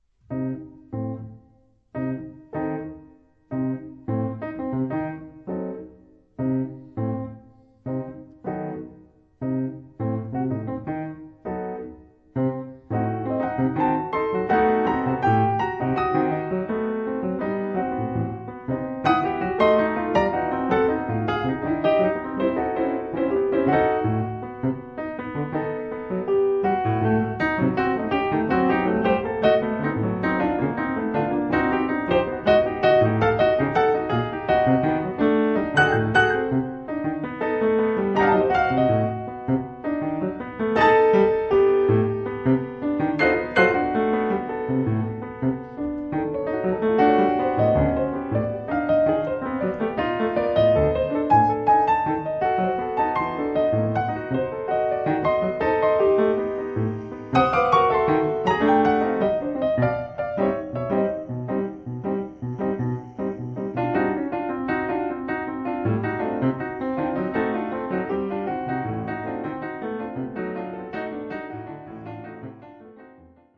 pianos
at Studio La Buissonne